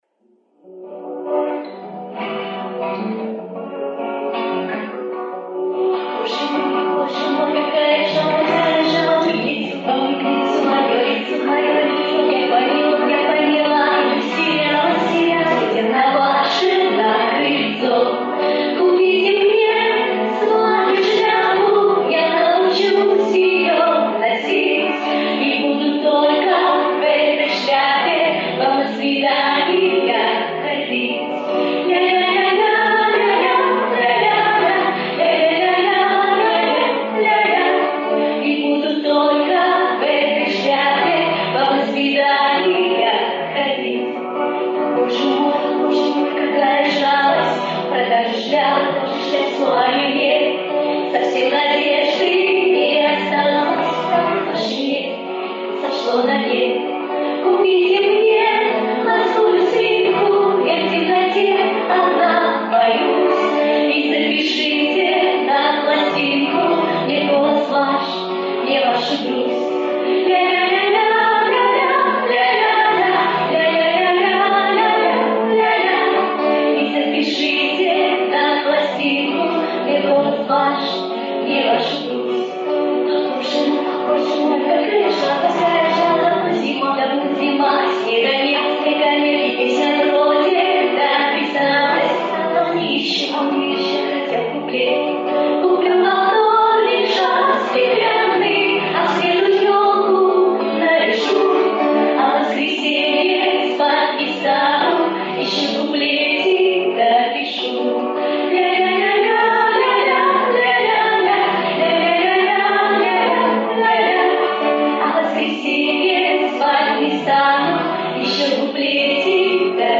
Запись концерта рыбинского клуба авторской песни.